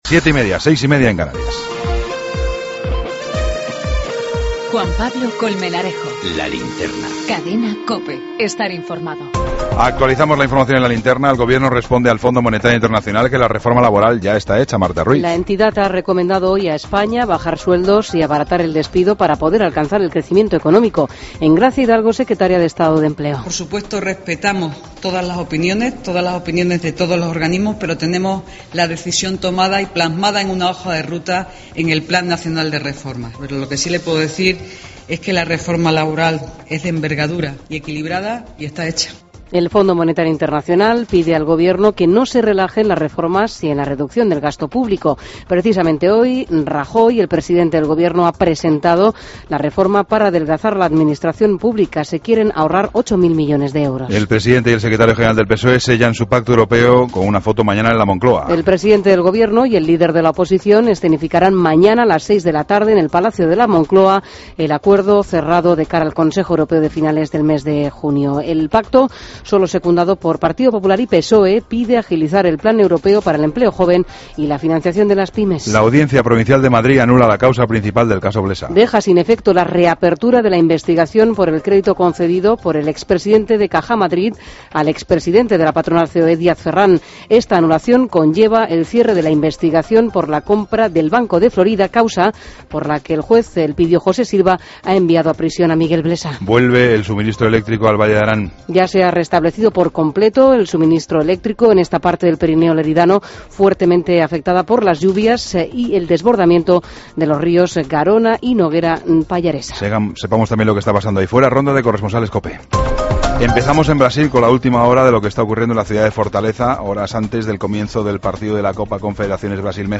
AUDIO: Toda la información con Juan Pablo Colmenarejo. Ronda de corresponsales. La ciencia, con Jorge Alcalde.